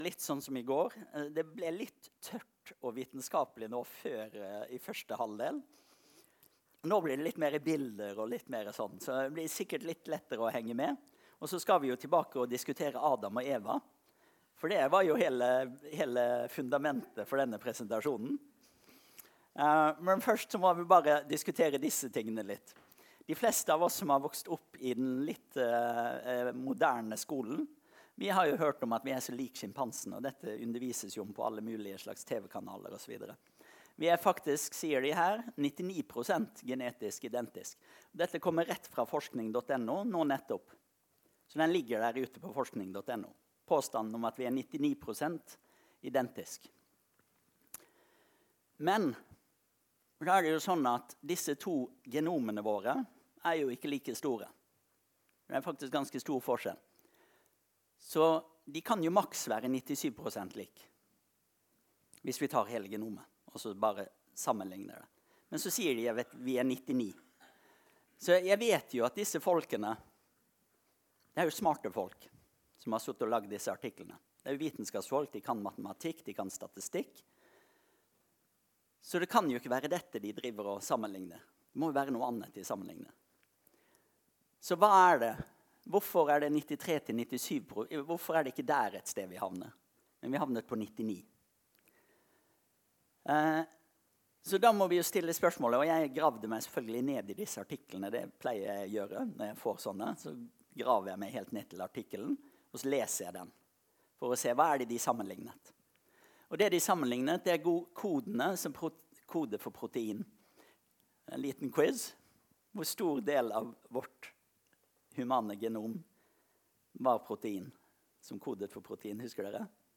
Møte: Seminar